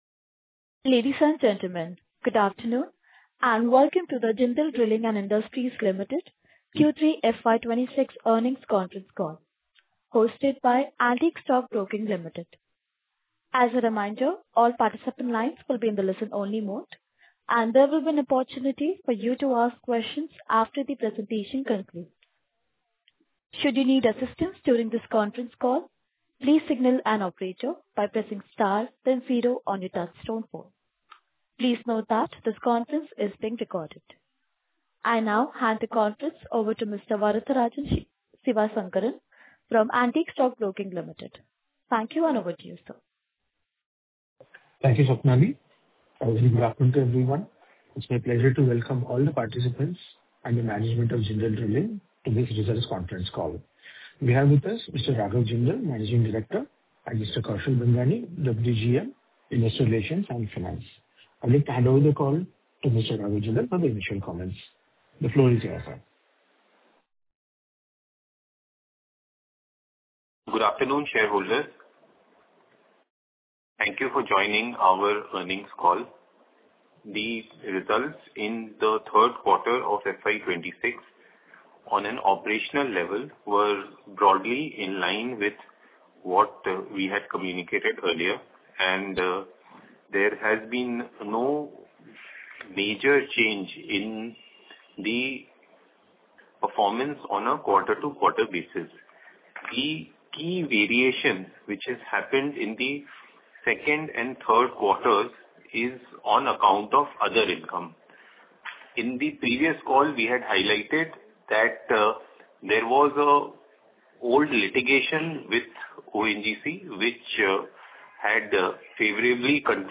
Concalls
Audio-Recording-Q3-FY26-Earnings-Conference.mp3